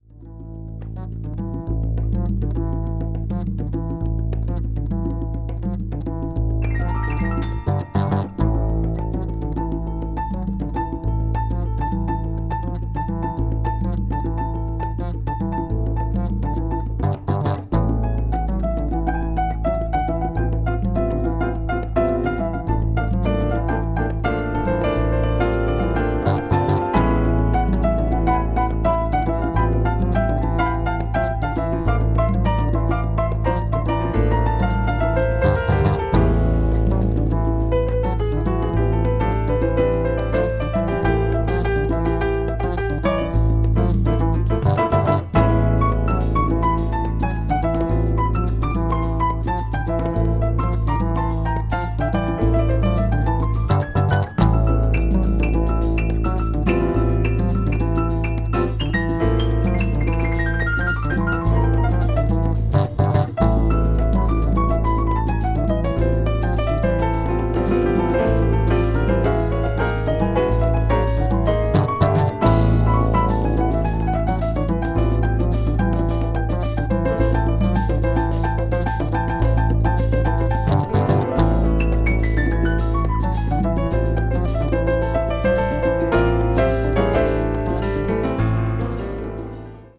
media : EX/EX(わずかにチリノイズが入る箇所あり)
contemporary jazz   crossover   fusion   soul jazz